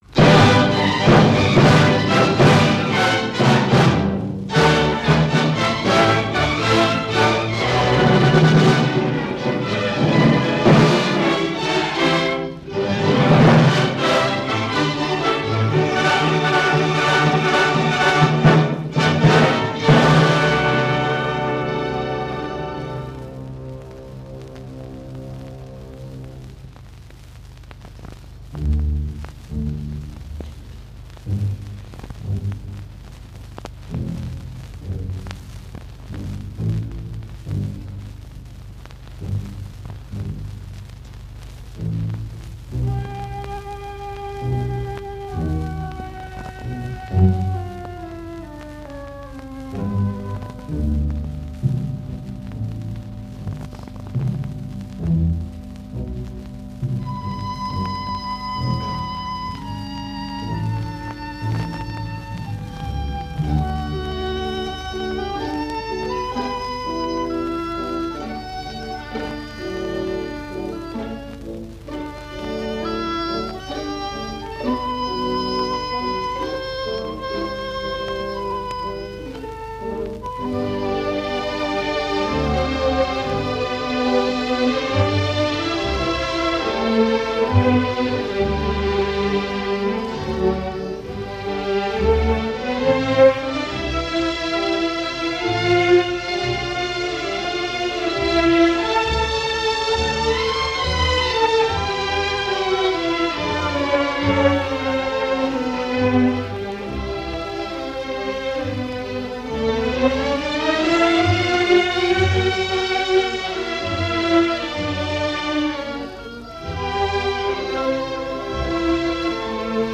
Which is why this particular concert recording from December 1, 1941 stands out. From what was undoubtedly a complete concert broadcast from Radio Suisse Romande in Geneva, the only remaining (so far) recording is this one; Overture to Benvenuto Cellini by Hector Berlioz.
The original recording is a bit creaky in spots but easily ignored by what was a “no-holds-barred” performance after the first few seconds.